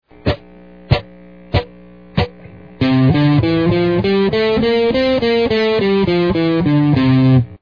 Scales and Modes on the Guitar
C-Mixolydian.mp3